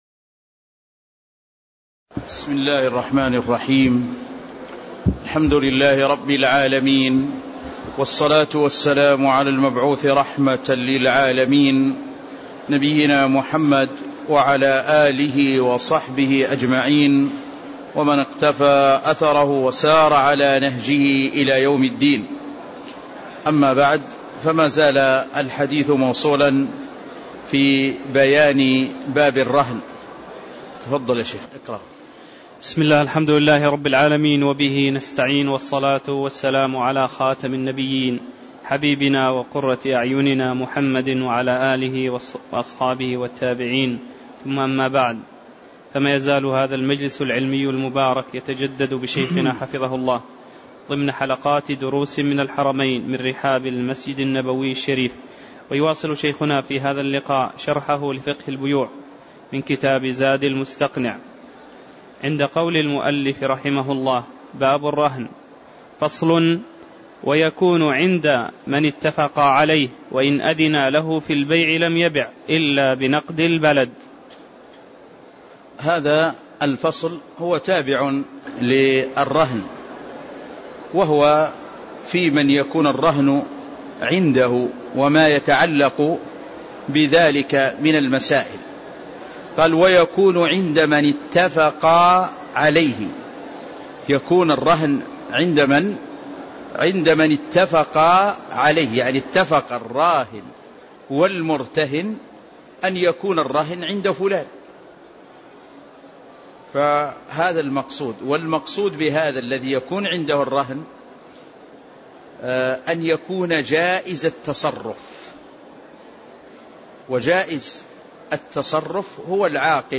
تاريخ النشر ١٤ محرم ١٤٣٧ هـ المكان: المسجد النبوي الشيخ